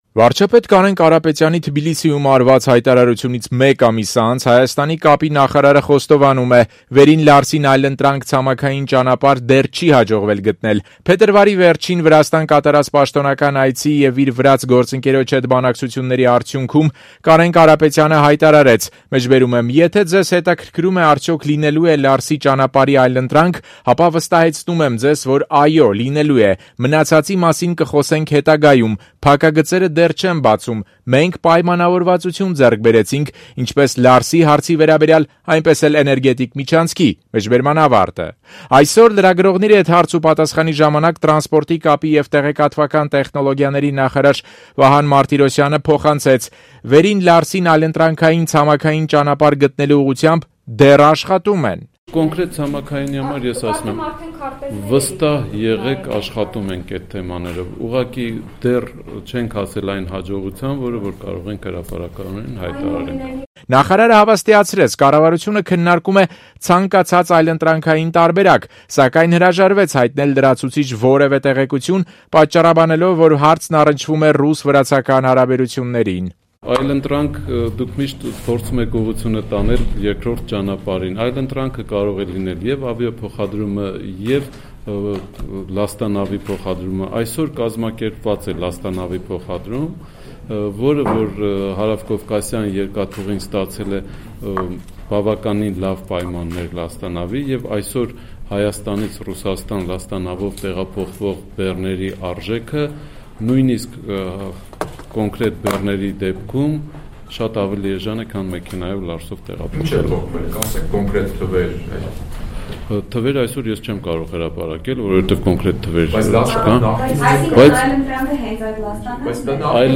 Ռեպորտաժներ